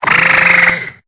Cri de Batracné dans Pokémon Épée et Bouclier.